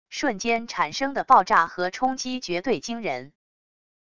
瞬间产生的爆炸和冲击绝对惊人wav音频